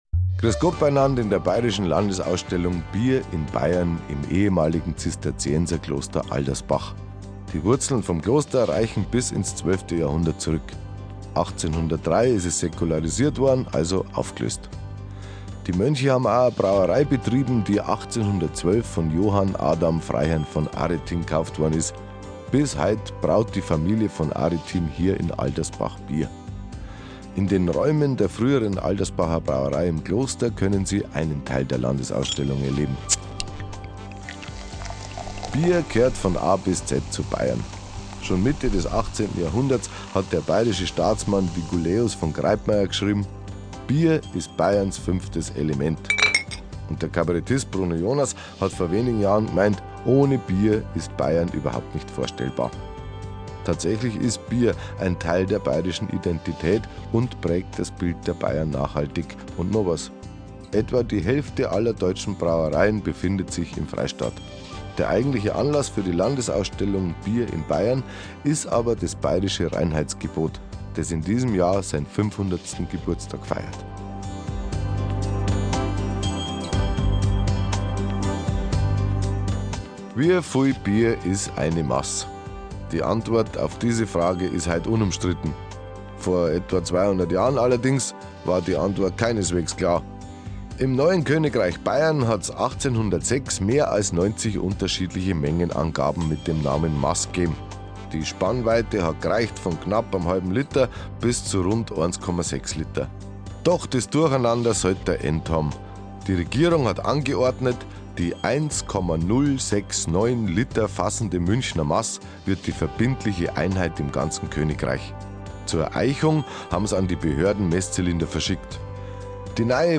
In Kooperation mit Bayern2 hat der Kabarettist Hannes Ringlstetter den Audioguide auf Bairisch eingesprochen. In amüsant-süffigem Ton stellt er die 50 interessantesten Exponate der Landesausstellung vor.
Audioguide.mp3